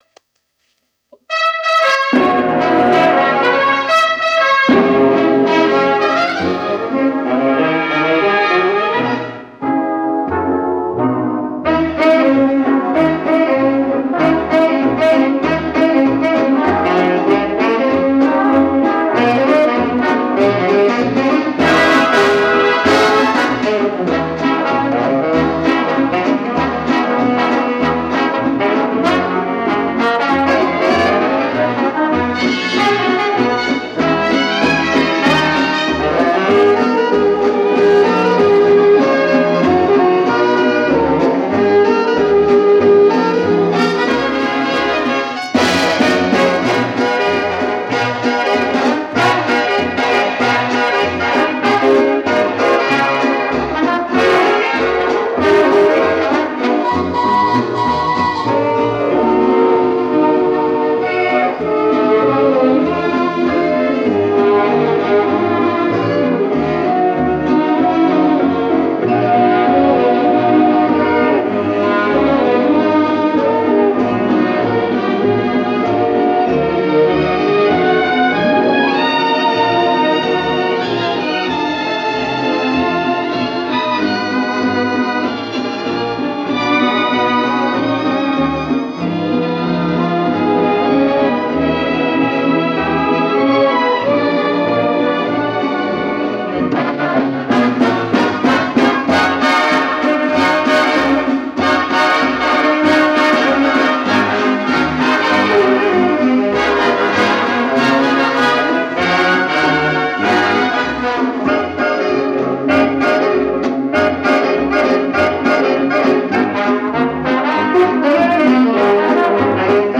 фокстрот
Вот почищенные от шума варианты.